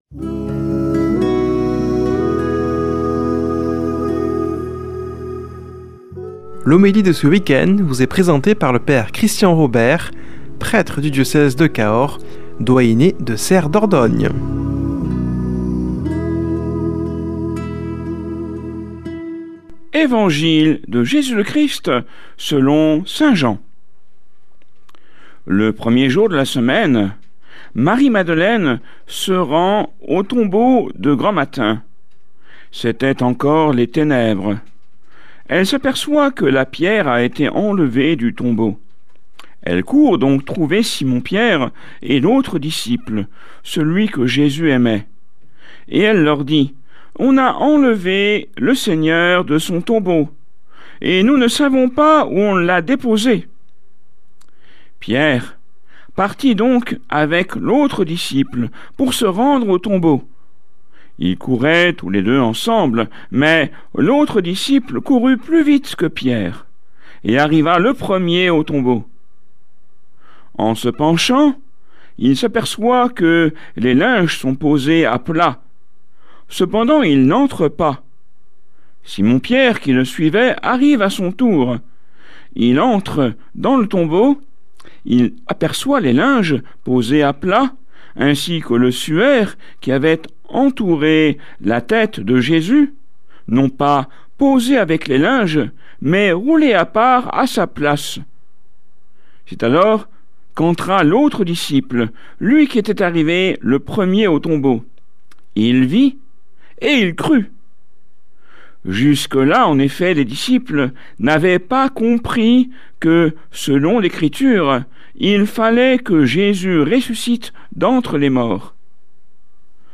Homélie du 05 avr.